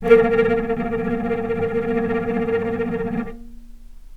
vc_trm-A#3-pp.aif